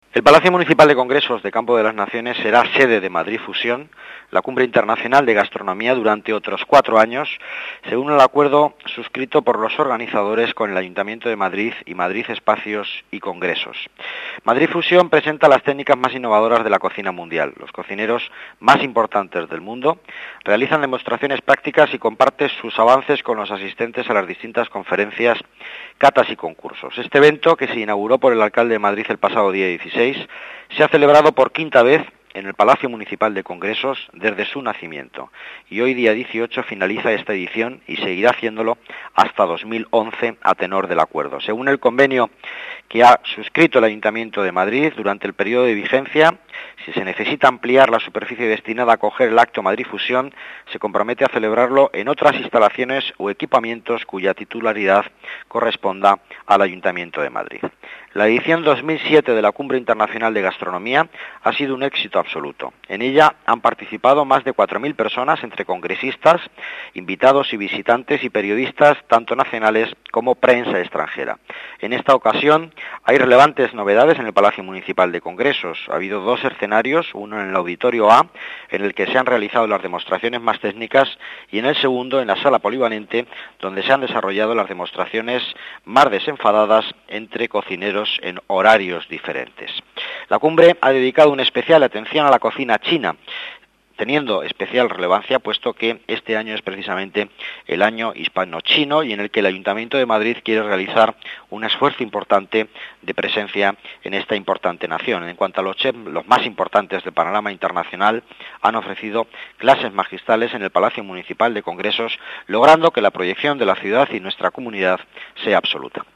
Nueva ventana:Declaraciones consejero delegado Economía, Miguel Ángel Villanueva: Madrid Fusión